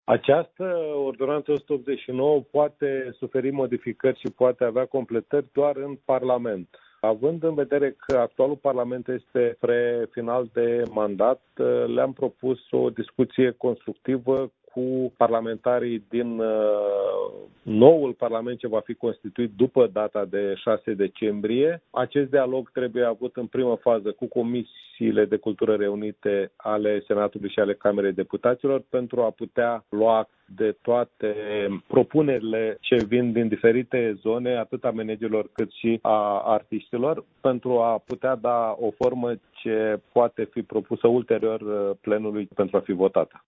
În ceea ce priveşte modificarea legii care reglementează managementul în instituţiile de cultură, ministrul Bogdan Gheorghiu a mai declarat, tot la Radio Iaşi, că a propus reprezentanţilor sindicatelor din instituţiile de profil să reia discuţiile după alegerea noului Parlament, în Decembrie: